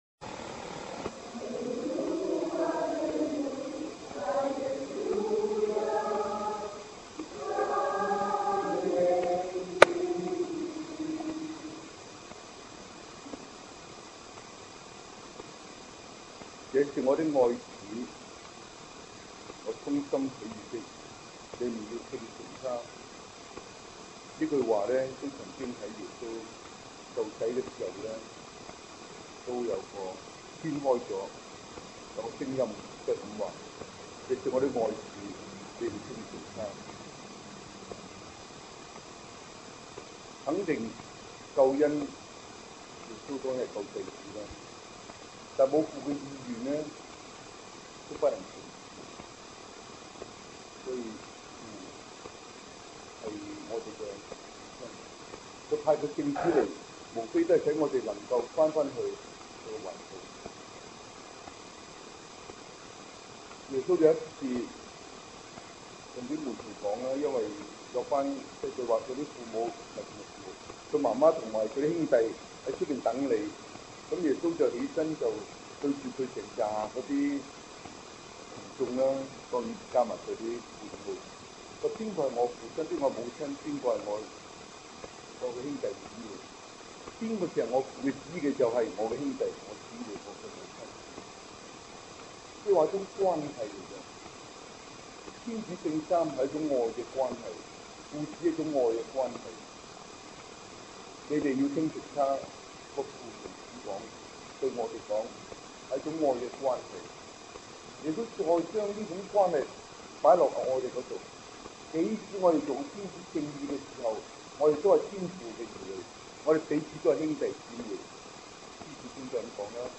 神父講道 2012年8月
聖心彌撒